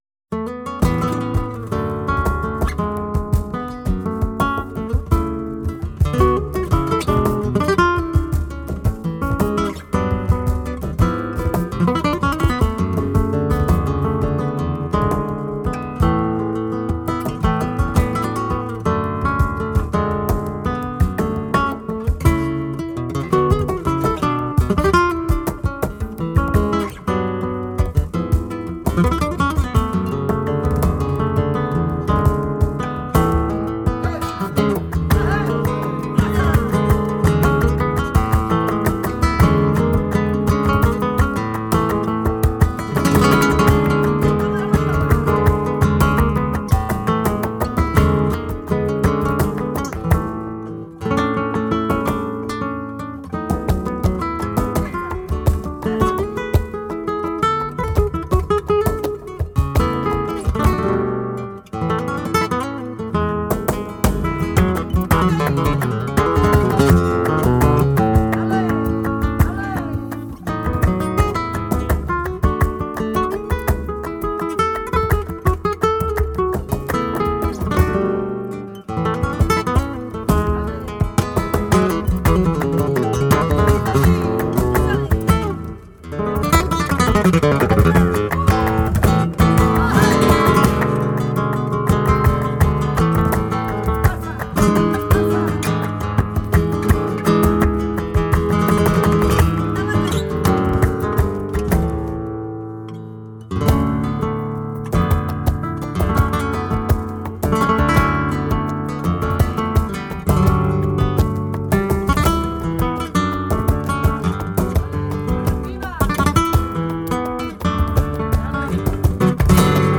composition et guitare